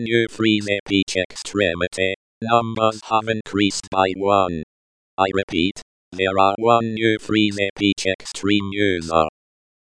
a message through espeak -s120 -v other/en-sc.